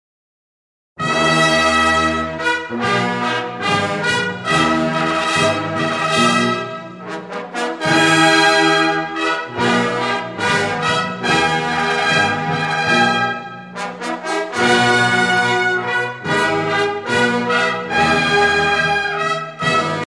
sonnerie réglementaire - Cérémonial militaire
Pièce musicale éditée